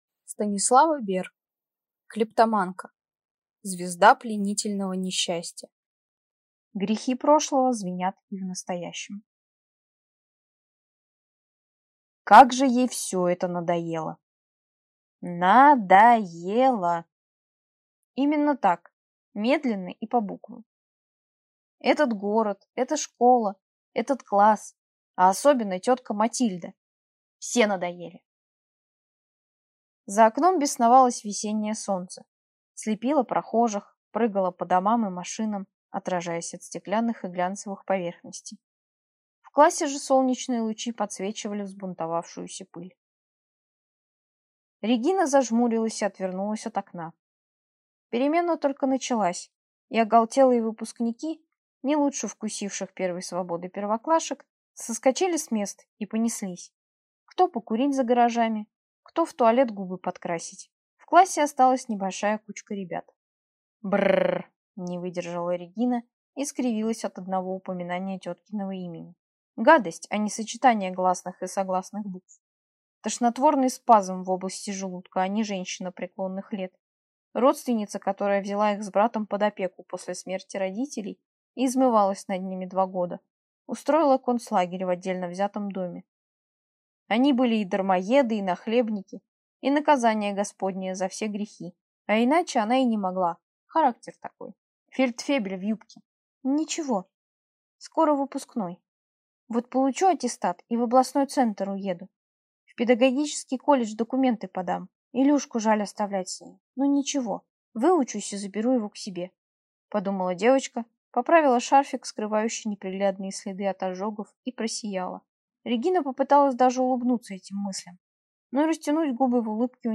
Аудиокнига Клептоманка. Звезда пленительного несчастья | Библиотека аудиокниг